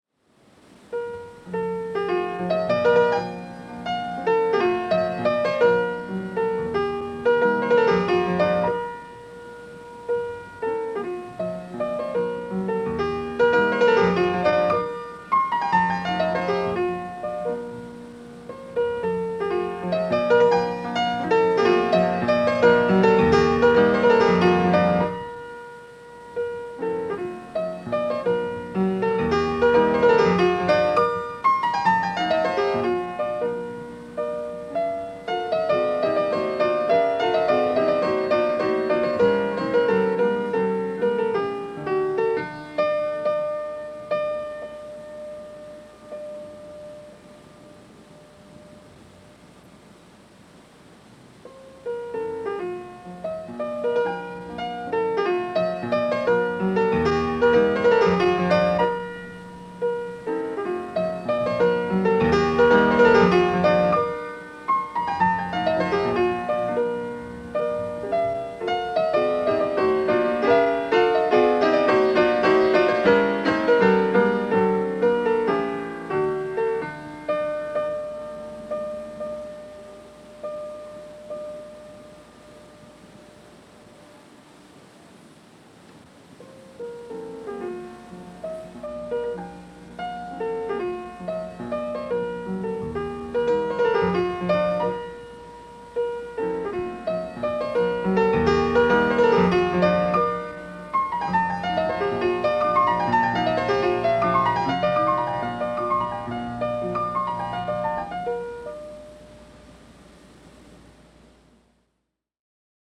en vivo, año 1982
piano
en do mayor